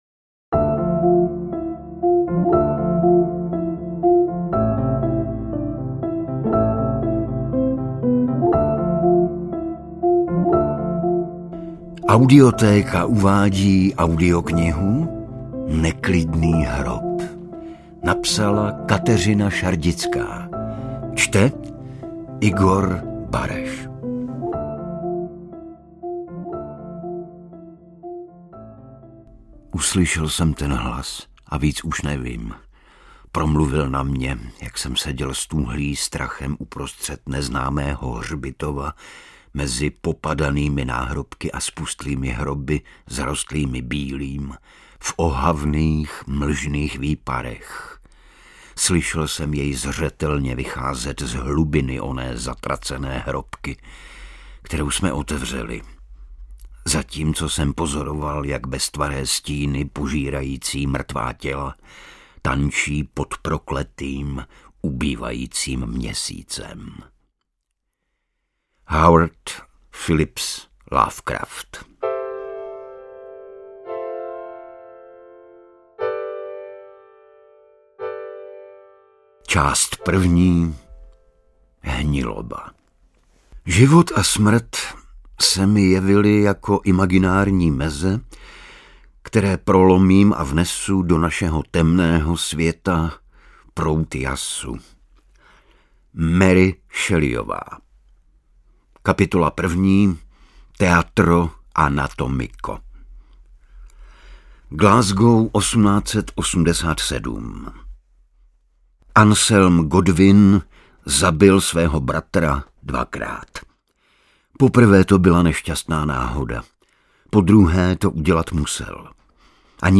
Audiobook
Read: Igor Bareš